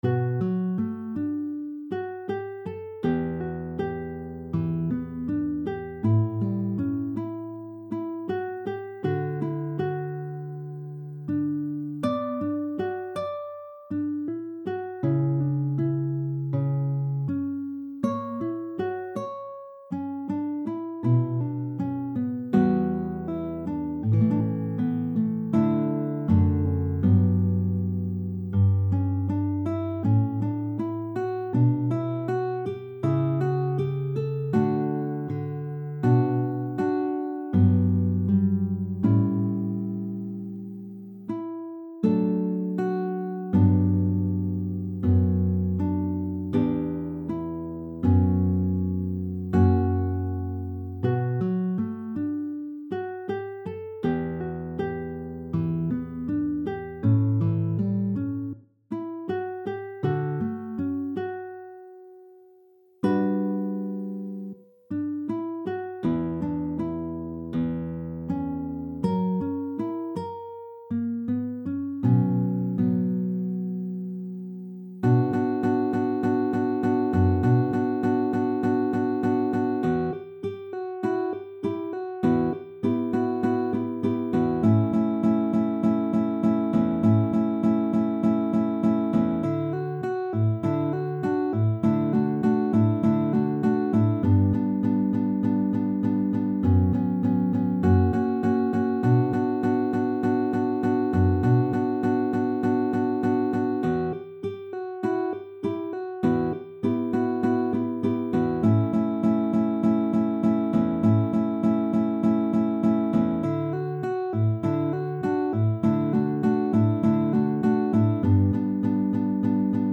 su chitarra